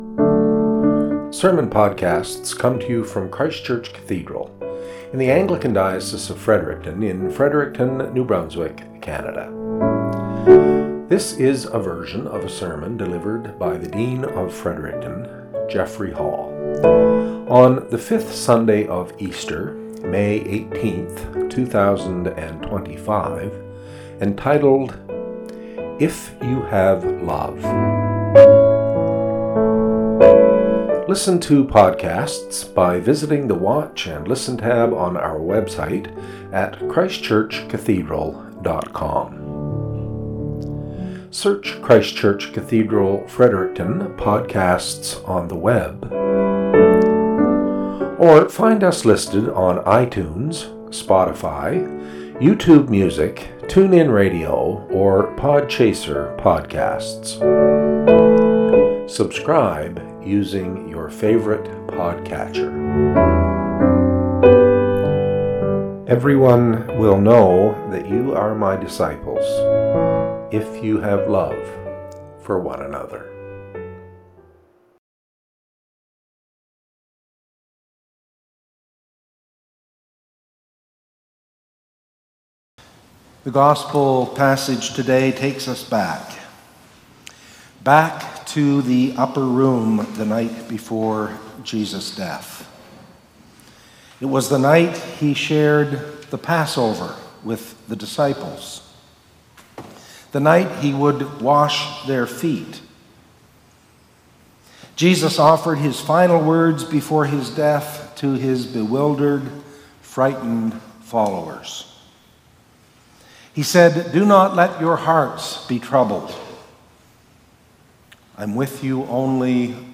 Cathedral Podcast - SERMON -